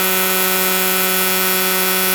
STRIMMER_Run_2sec_loop_mono.wav